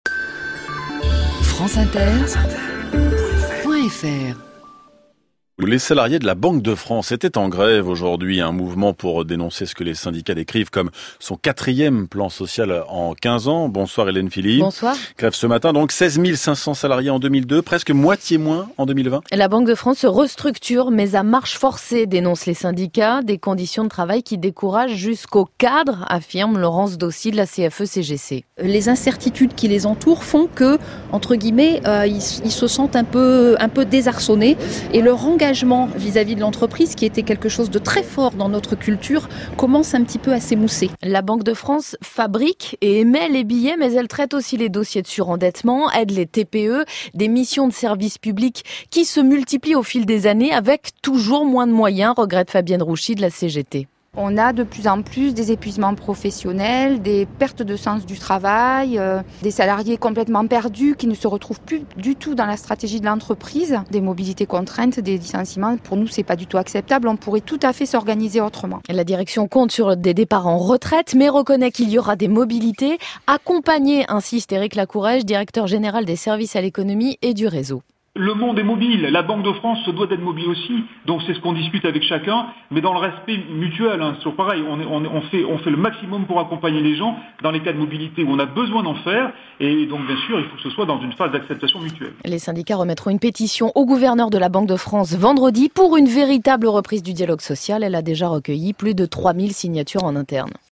Reportage sur France inter (journal de 19h le 14 novembre)